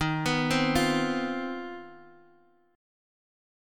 CmM11/Eb chord